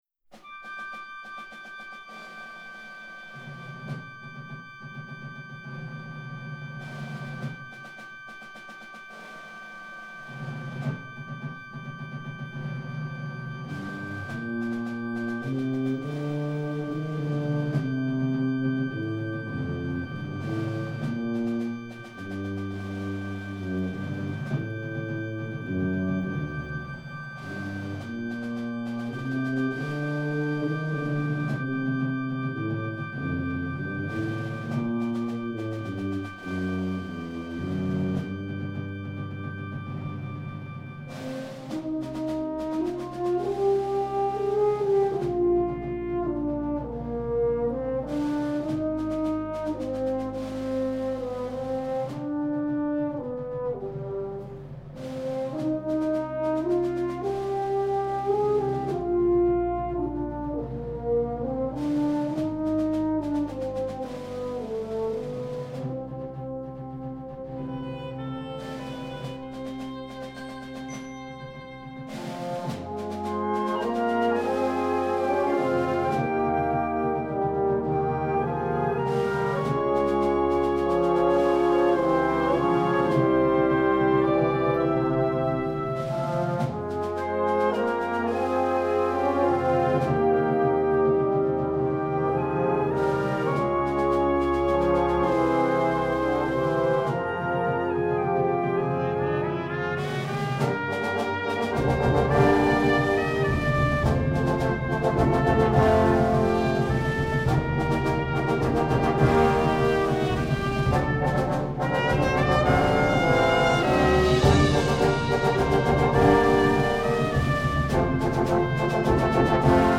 Instrumentation: concert band